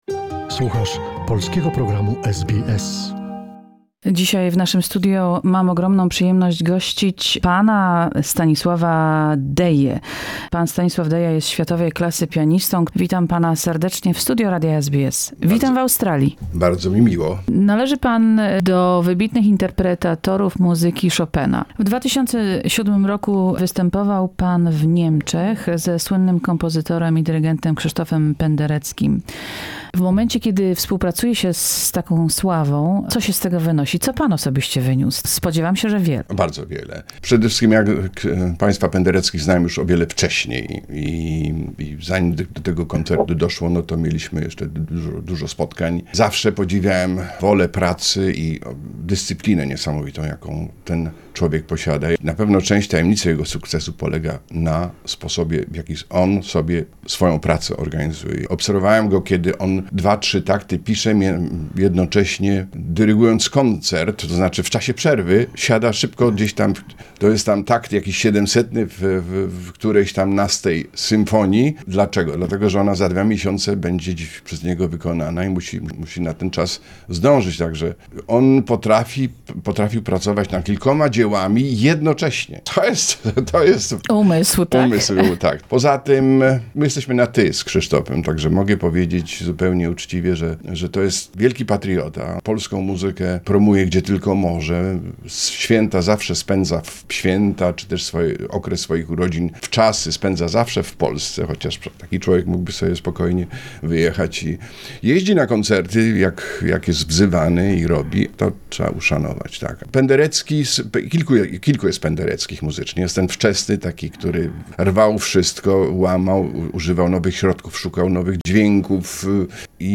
...such had proved a renowned Polish pianist visiting Sydney's SBS studios by fusing Australian and Polish music icons in one universal musical statement of his love for Australia.